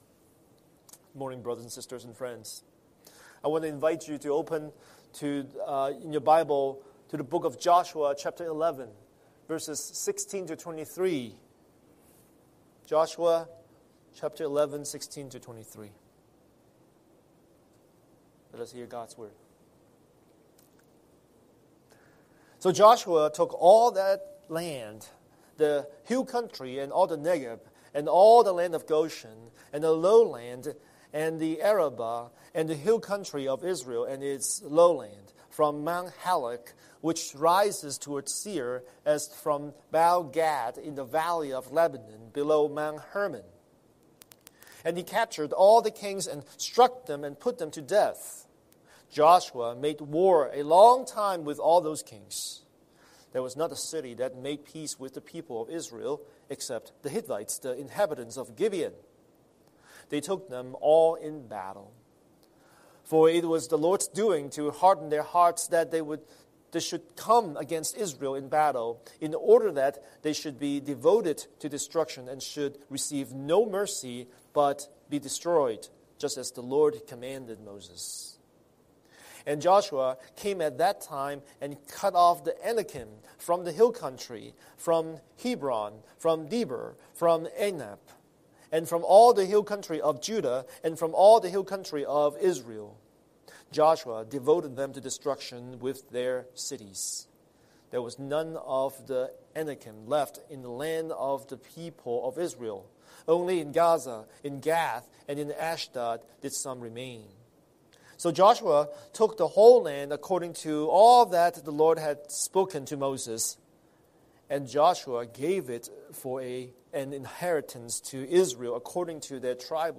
Scripture: Joshua 11:16–23 Series: Sunday Sermon